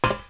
metalwalk2.wav